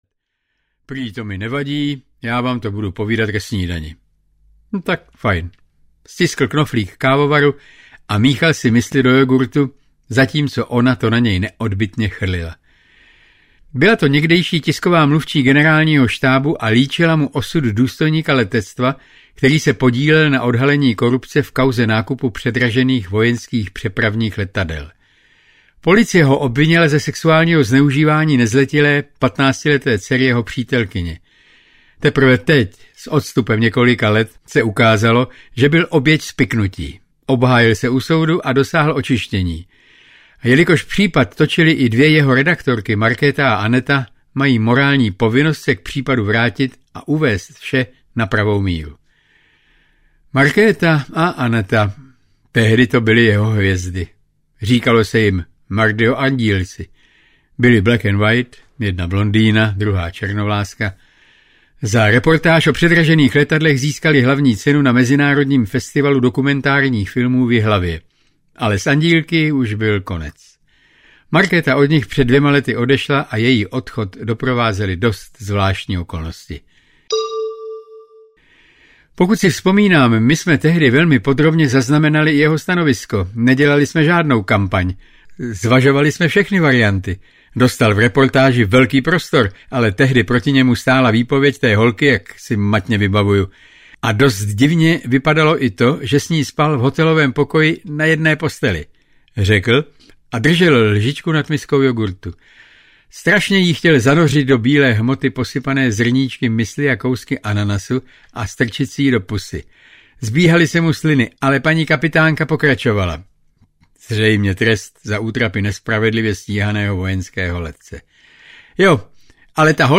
Marek Wollner - Reportér na odstřel audiokniha
Ukázka z knihy